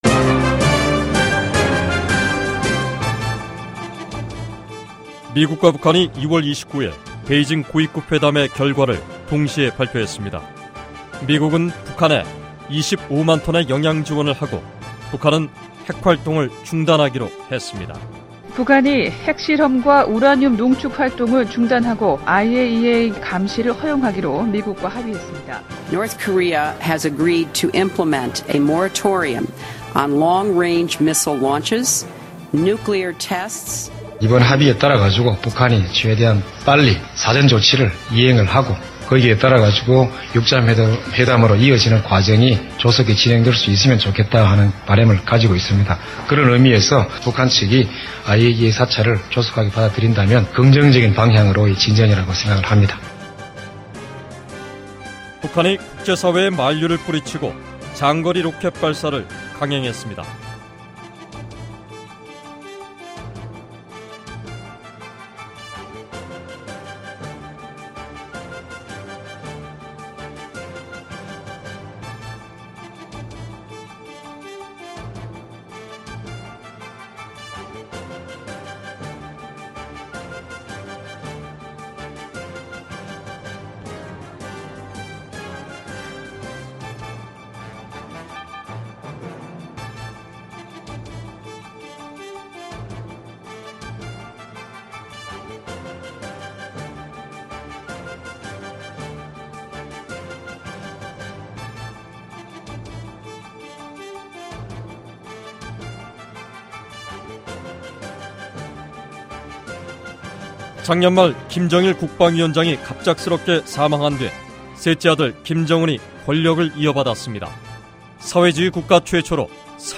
저희 VOA는 한 해를 마감하면서 북한 김정은 정권 1년을 돌이켜 보는 특집방송을 준비했습니다.